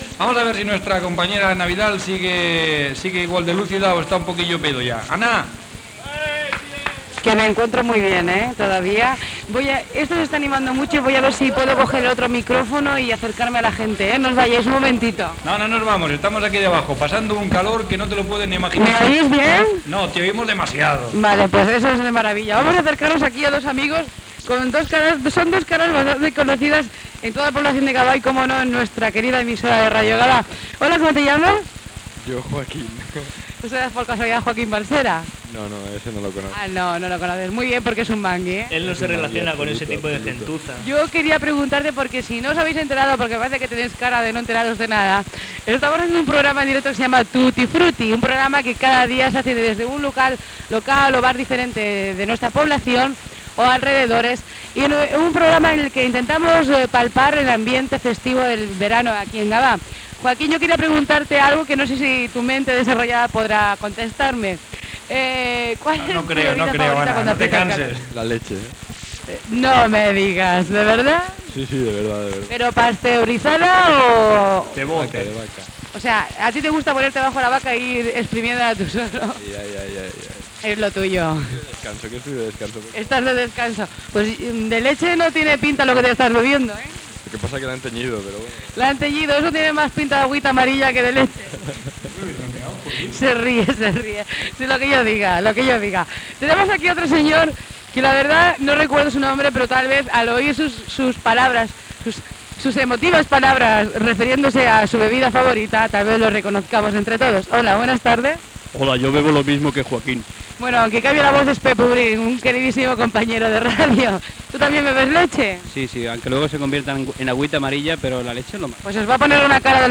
f09126d4b2fd6d7db435a4bd1221ac827cbad4db.mp3 Títol Ràdio Gavà Emissora Ràdio Gavà Titularitat Pública municipal Nom programa Tutti frutti (Ràdio Gavà) Descripció Entrevistes estiuenques a ciutadans de Gavà.